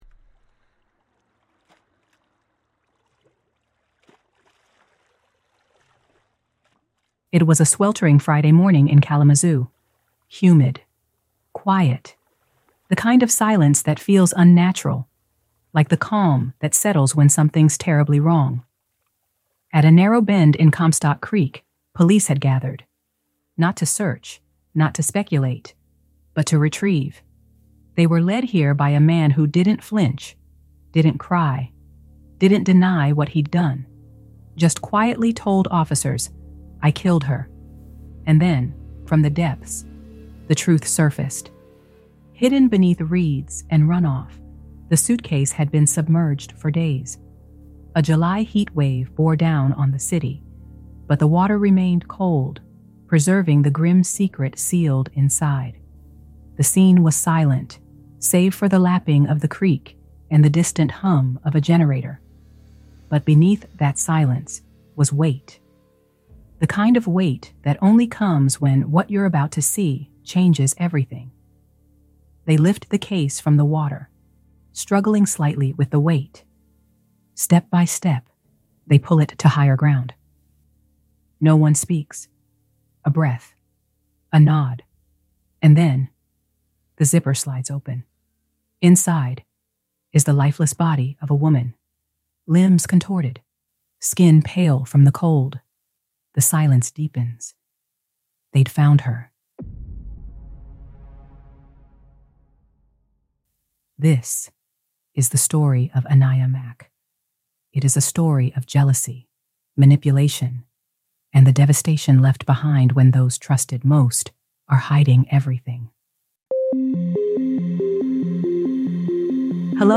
The audio is pulled from our YouTube channel and remastered for an immersive podcast experience, but if you’d like to see the visuals that accompany these cases, you can find them linked in every episode.